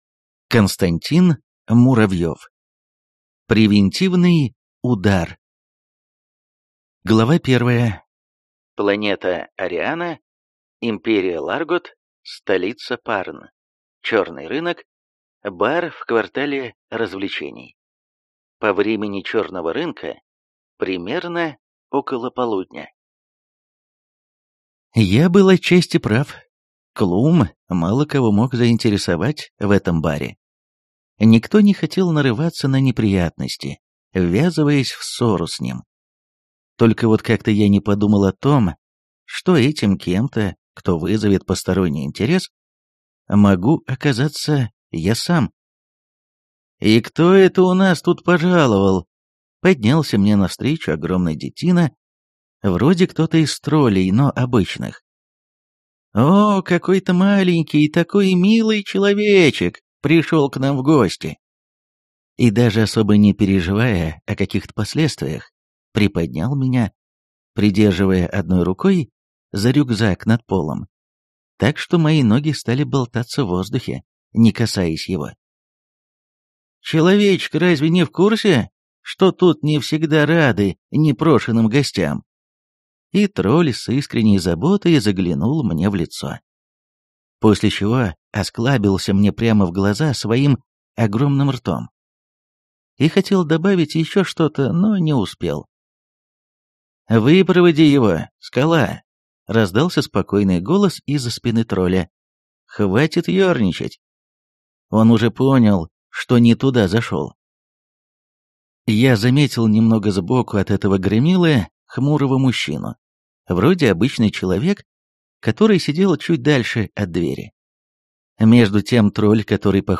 Аудиокнига Пожиратель. Превентивный удар | Библиотека аудиокниг